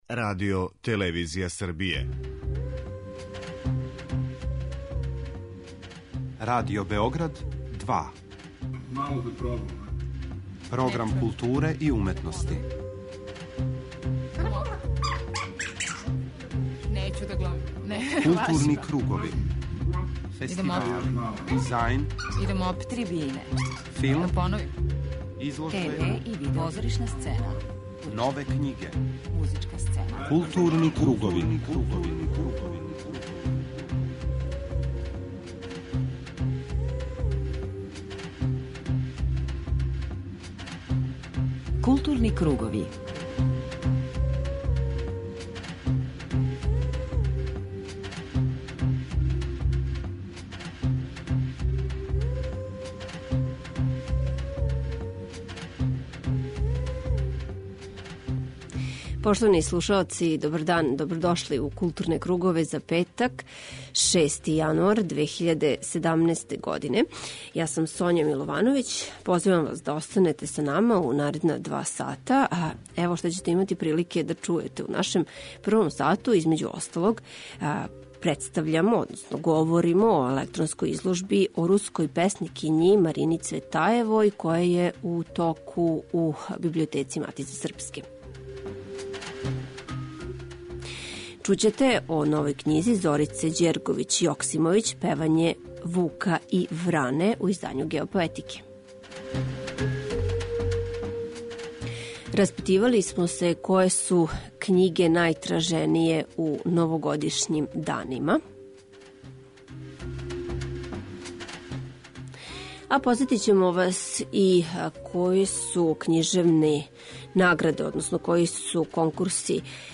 У магазинском делу емисије, између осталог, књижари и читаоци говоре о томе које књиге се купују и читају ових дана.